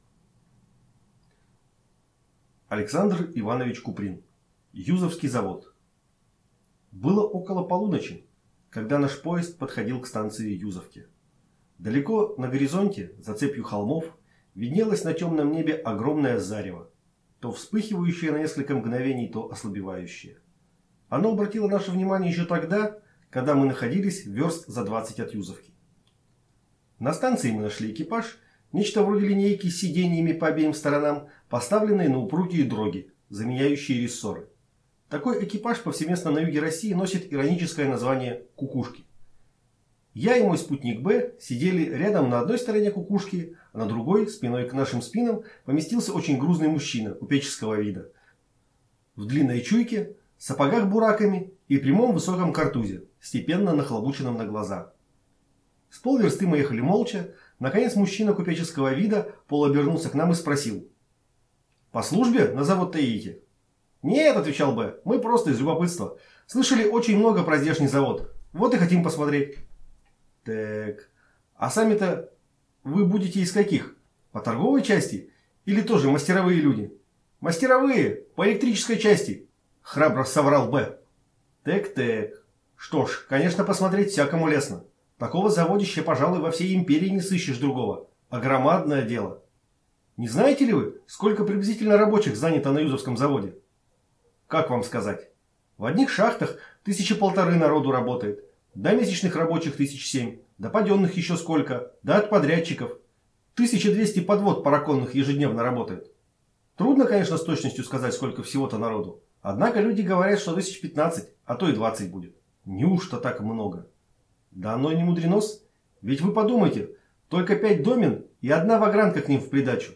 Аудиокнига Юзовский завод | Библиотека аудиокниг